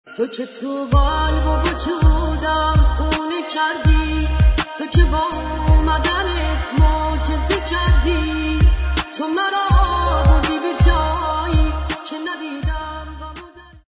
ساخته شده با هوش مصنوعی